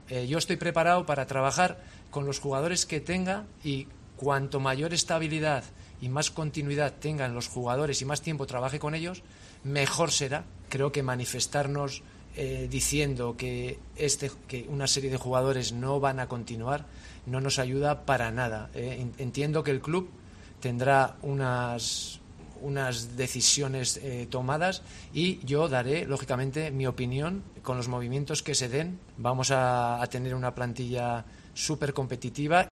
AUDIO. Javi Gracia ha esquivado las respuestas sobre jugadores concretos